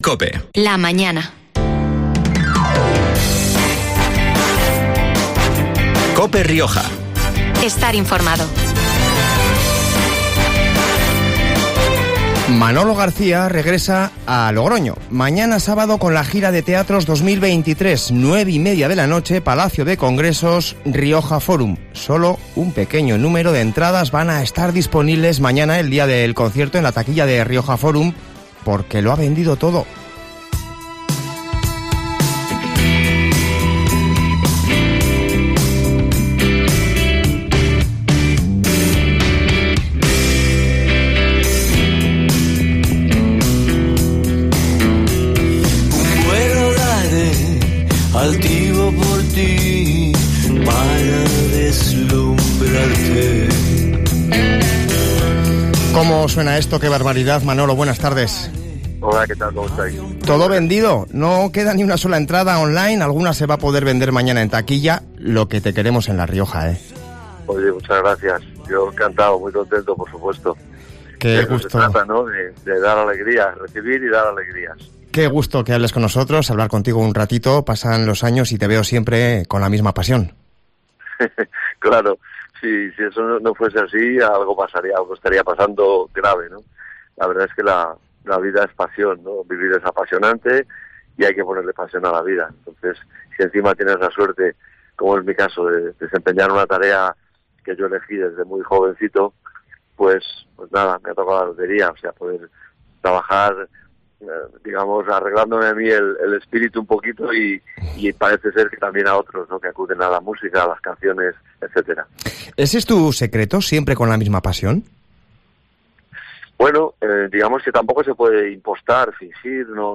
En COPE hablamos con el artista que confiesa que tiene "alma y esp
Hoy ha pasado por los micrófonos de COPE para contarnos qué espera de su visita a La Rioja, una tierra que le gusta, que le inspira.